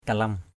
/ka-lʌm/ 1.
kalam.mp3